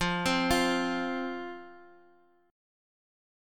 F5 chord